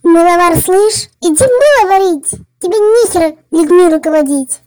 • Качество: 320, Stereo
голосовые